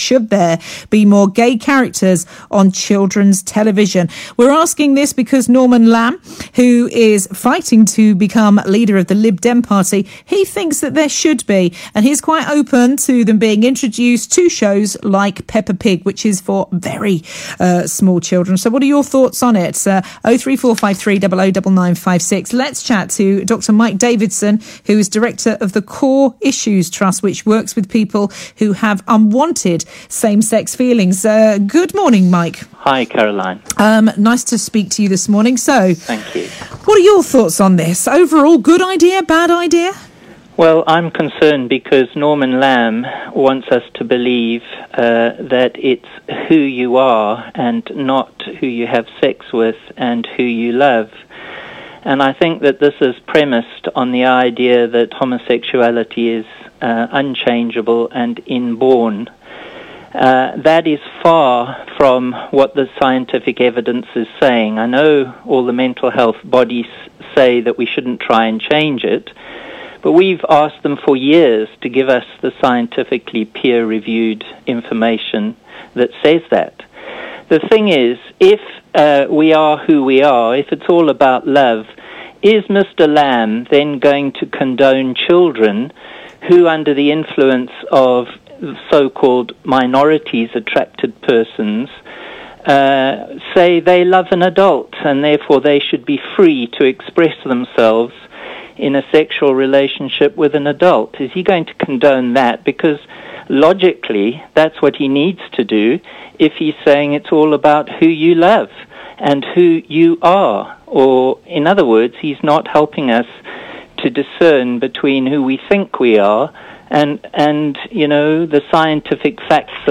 A discussion about Norman Lamb proposing gay cartoon characters in children's programming and whether this is a good idea. 18th October 2015